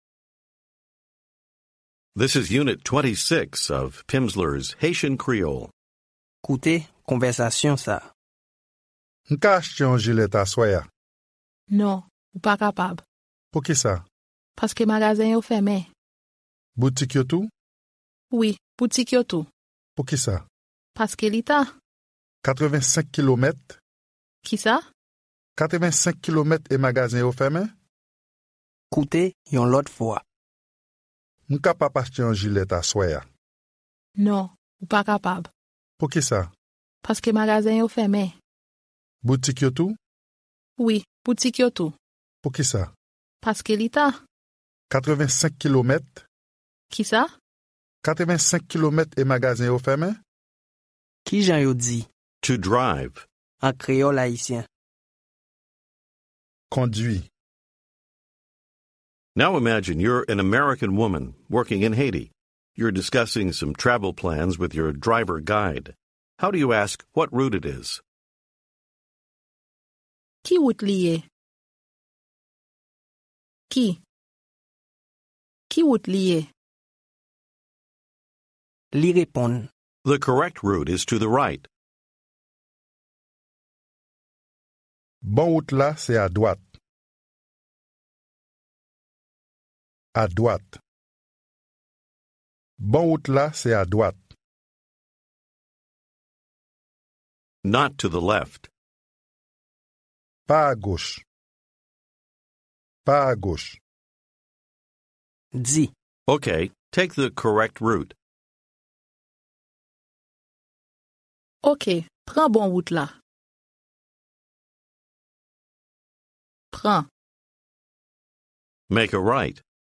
Audiobook
Haitian Creole Phase 1, Units 26-30 build on material taught in prior units. Each lesson provides 30 minutes of spoken language practice, with an introductory conversation, and new vocabulary and structures.